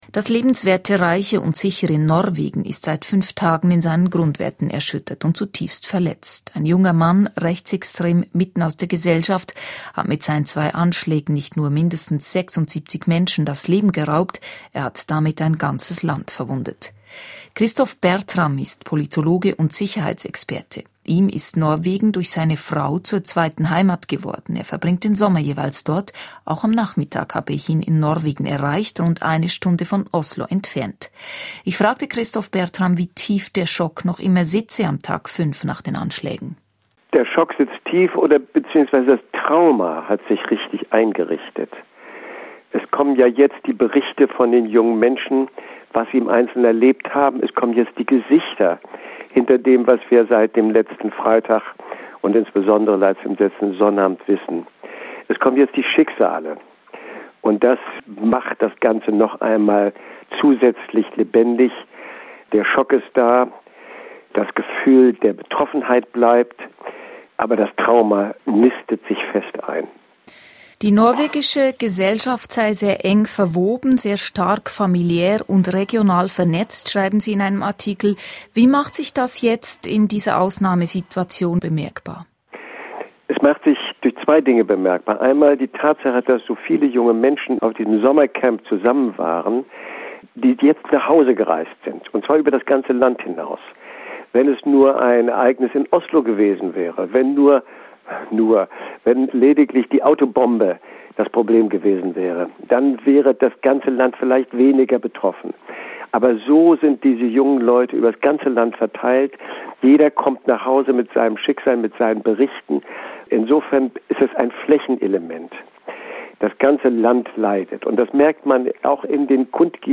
Das Land, das so stolz ist auf seine Jugend, ist in seinen Grundfesten erschüttert. Gespräch